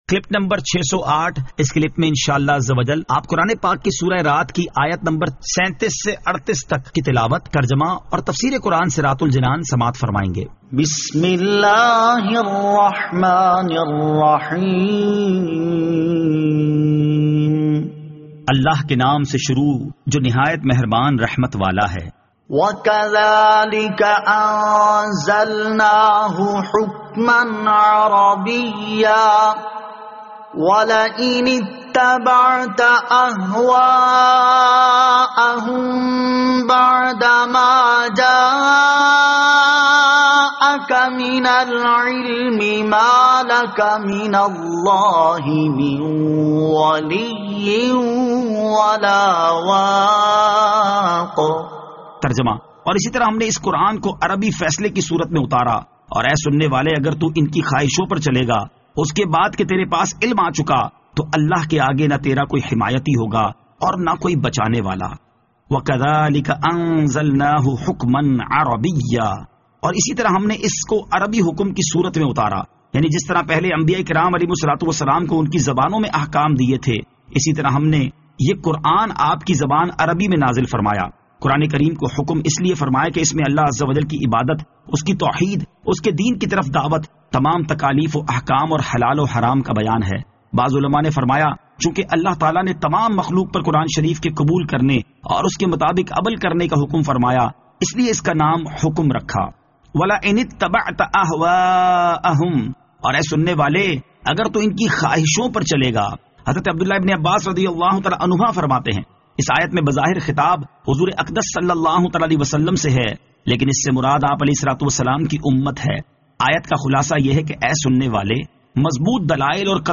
Surah Ar-Rad Ayat 37 To 38 Tilawat , Tarjama , Tafseer